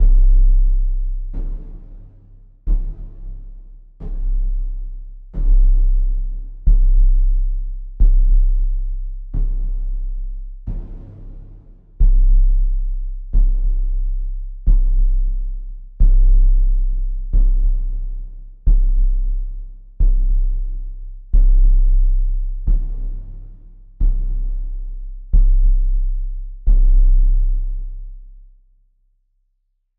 Звуки убийства, трупа
Шаги убийцы или как смерть идет за тобой